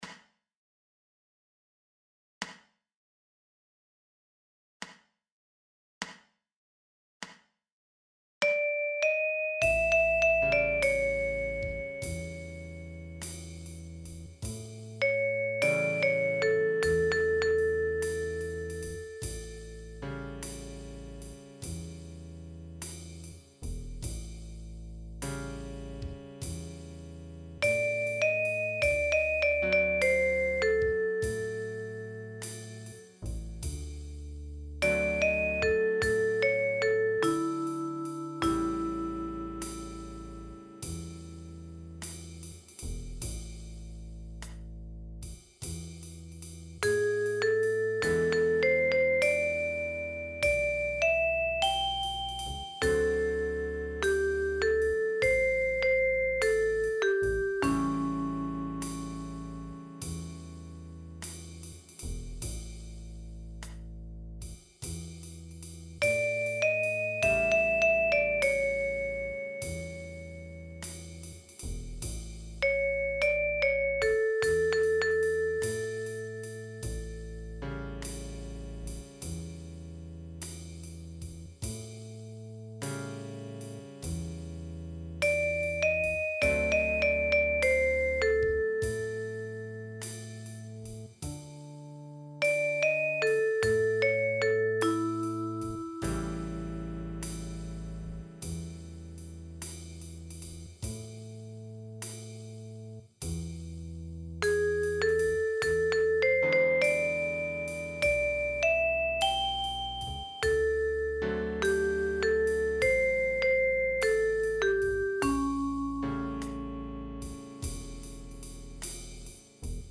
einfach notiert für die chromatische Mundharmonika.